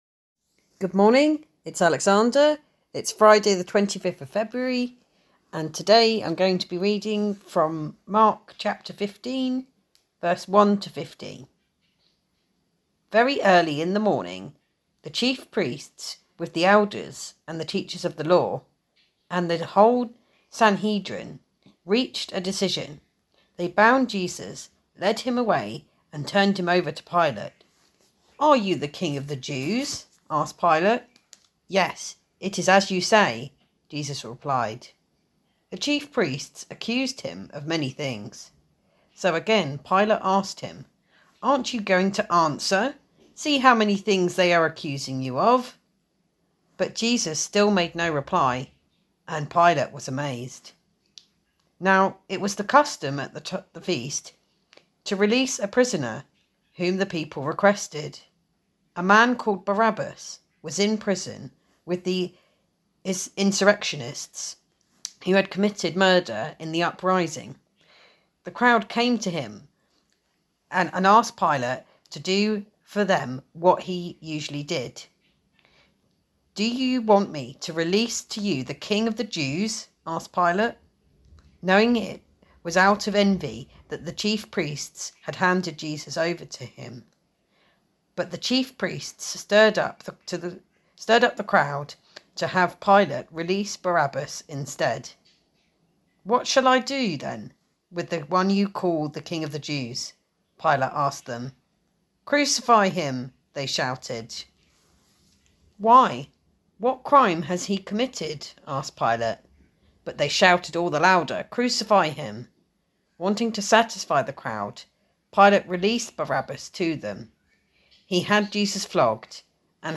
Today’s reading is Mark 15:1-15